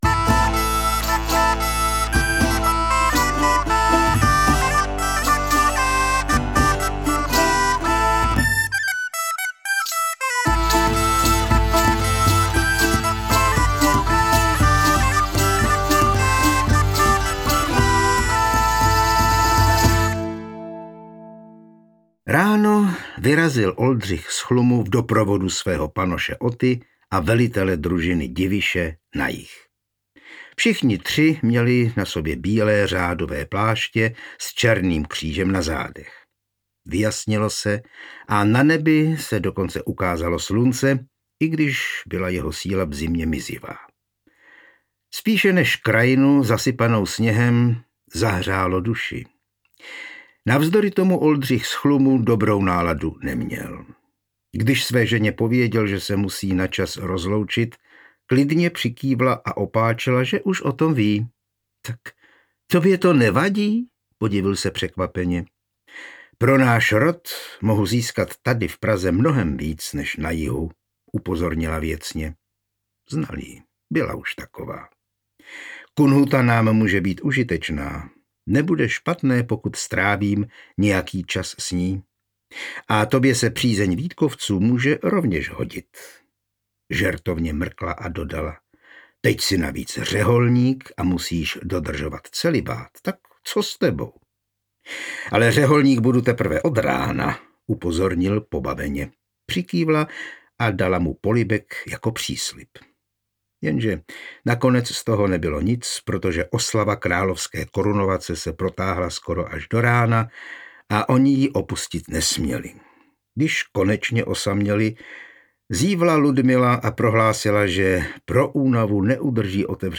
Ďáblův sluha audiokniha
Ukázka z knihy
• InterpretJan Hyhlík
dabluv-sluha-audiokniha